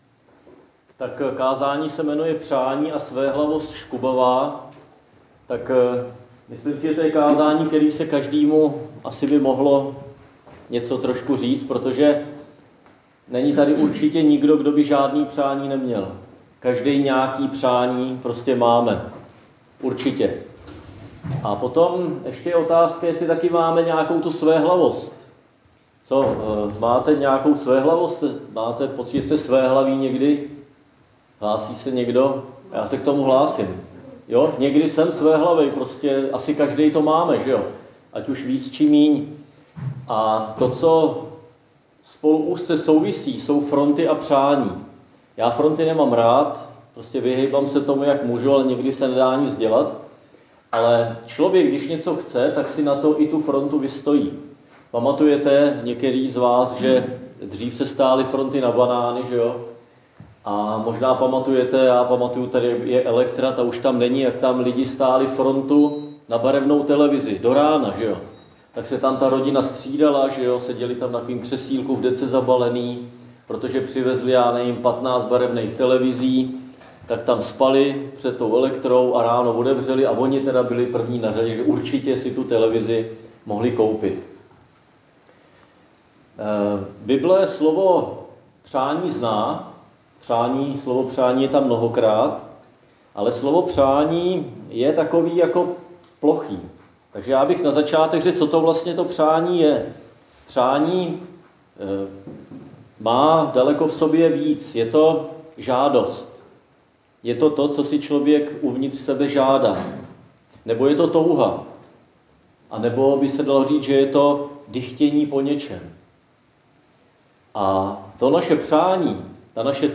Křesťanské společenství Jičín - Kázání 5.3.2023